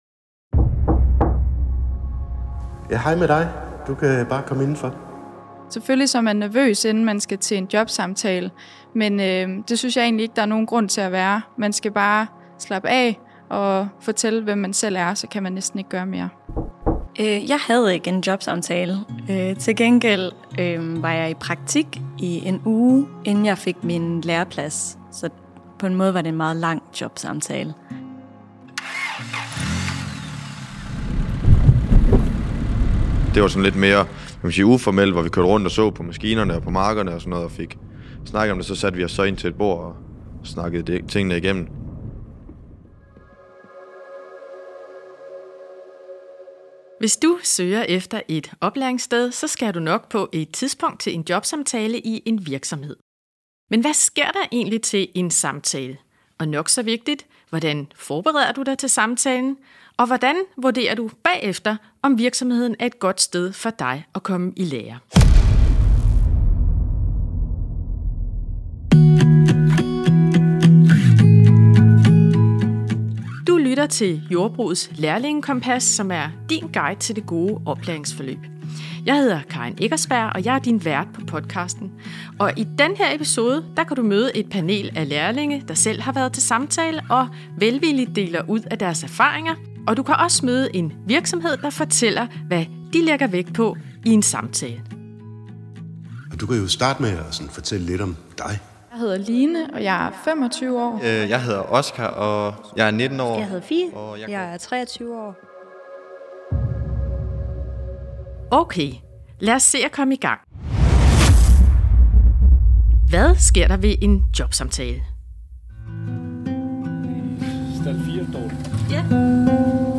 Du får erfaringer og gode råd fra et lærlingepanel, der selv har været igennem processen, og vi besøger en virksomhed, der fortæller, hvad de kigger efter hos nye lærlinge.